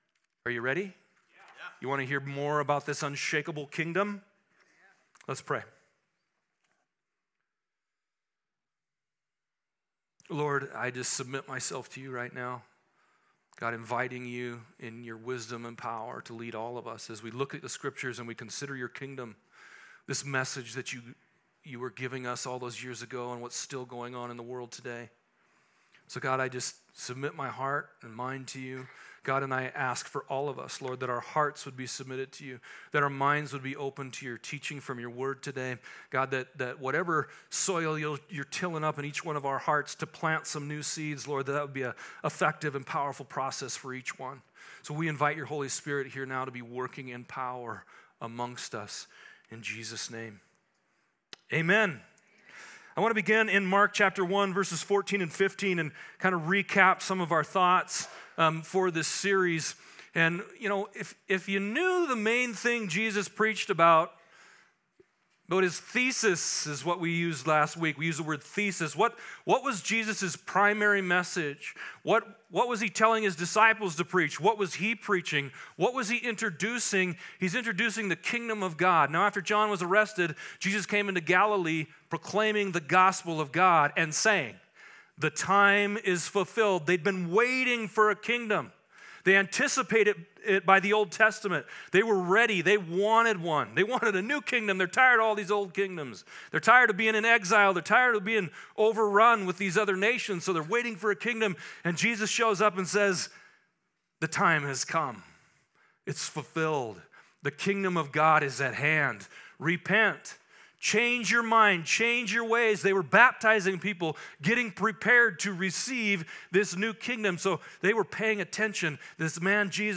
Dive in as we finish up our introduction to the UNSHAKABLE series where in this sermon we learn why it is so easy to miss God’s Kingdom.